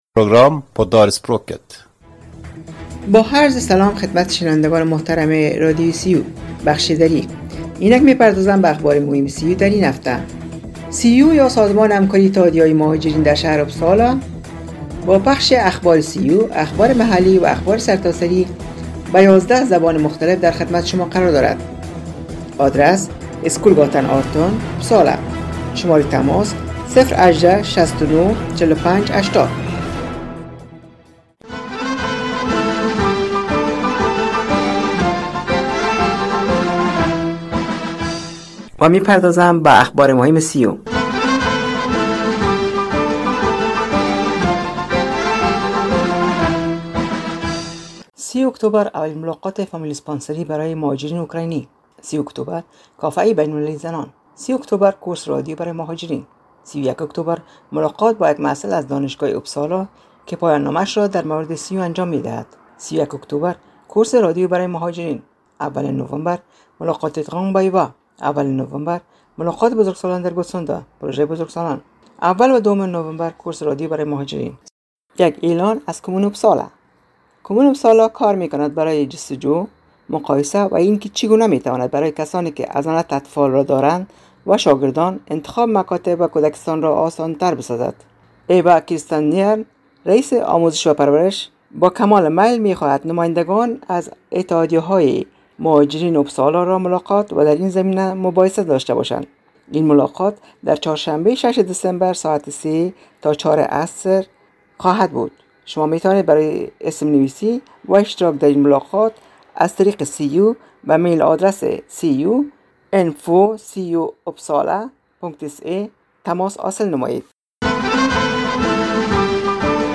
شنوندگان گرامی برنامه دری رادیو ریو یا انترنشنال رادیو در اپسالا سویدن روی موج ۹۸،۹ FM شنبه ها ازساعت ۸:۳۰ تا ۹ شب به وقت سویدن پخش میگردد که شامل اخبار سیو، اخبارمحلی و اخبارسرتاسری میباشد